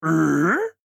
AV_bear_question.ogg